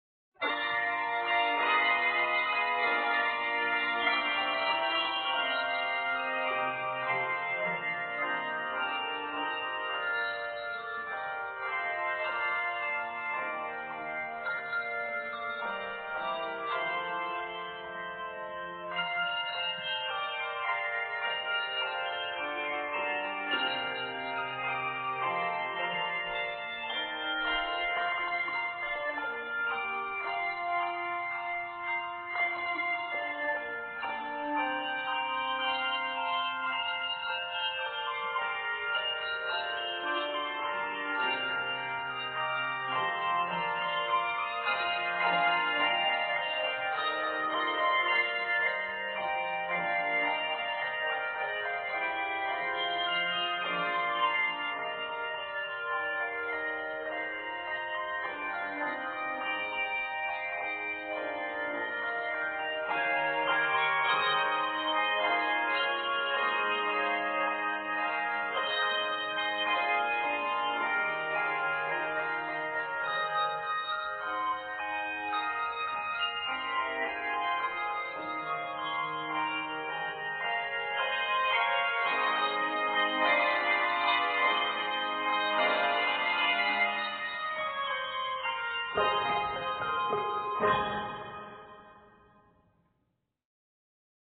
N/A Octaves: 4-5 Level